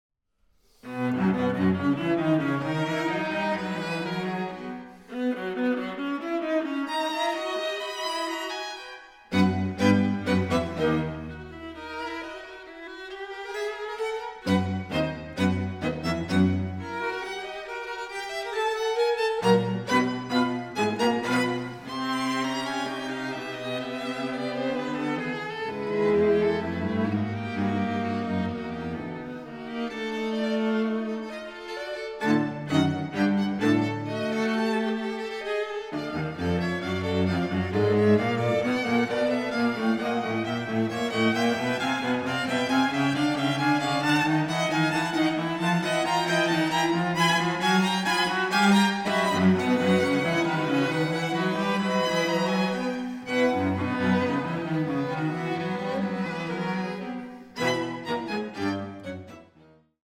Streichquartett
Aufnahme: Festeburgkirche Frankfurt, 2024